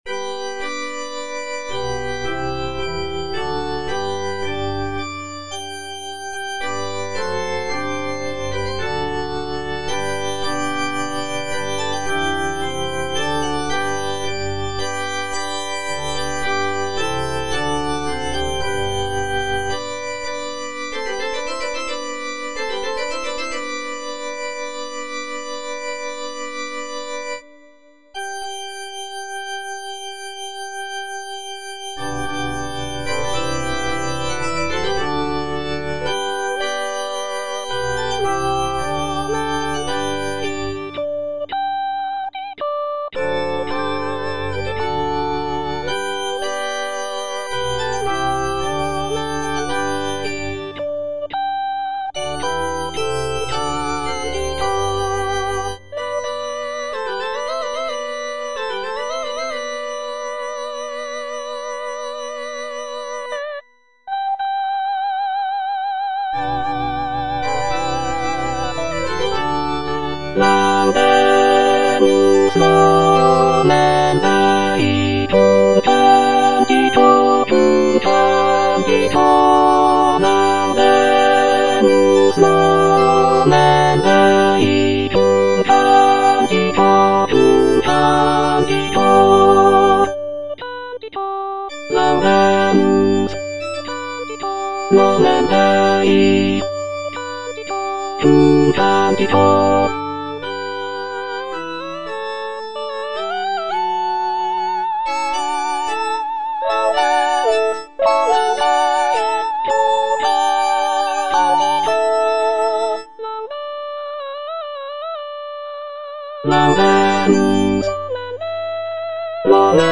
J.P. RAMEAU - IN CONVERTENDO Laudate nomen Dei - Alto (Emphasised voice and other voices) Ads stop: auto-stop
"In convertendo" is a sacred motet composed by Jean-Philippe Rameau in the 18th century. The piece is written for four voices and is based on a psalm text.